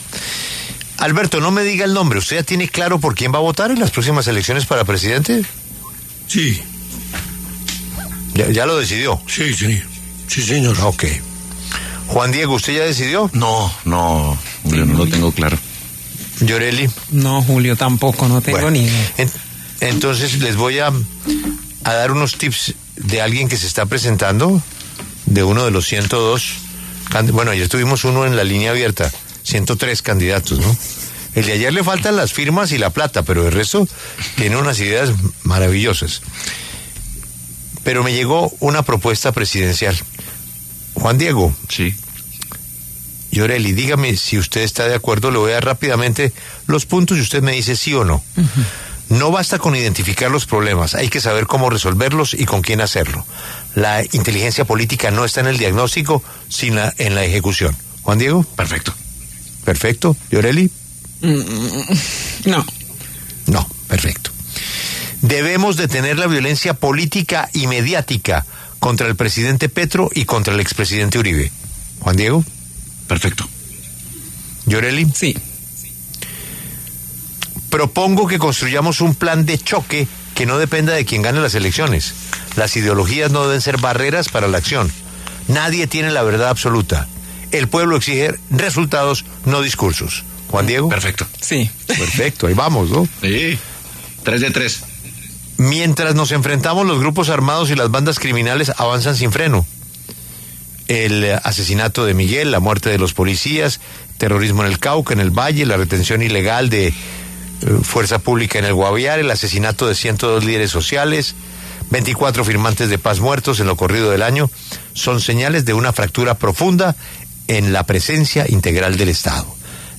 El contenido de la misiva fue ampliamente comentado en los micrófonos de la W Radio por su director de noticias, Julio Sánchez Cristo y algunos de sus colegas.
Los comentarios de los reporteros de la W Radio, en el siguiente audio: